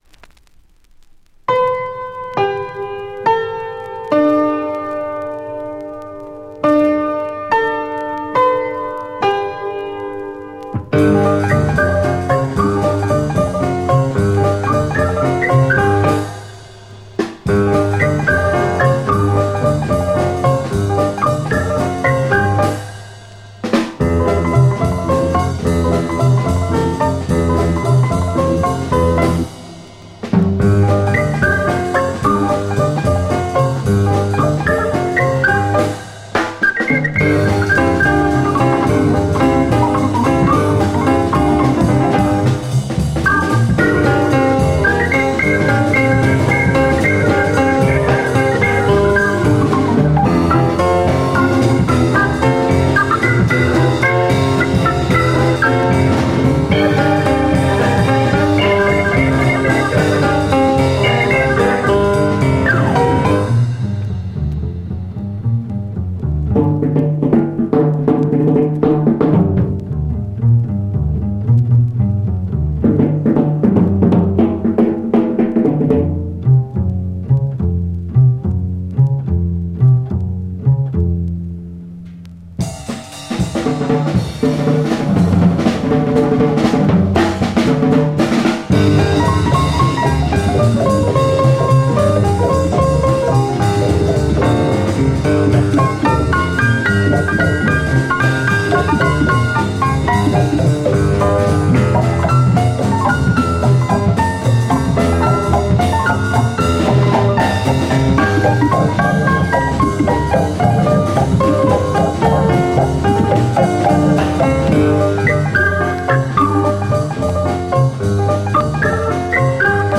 French pivate Jazz mod EP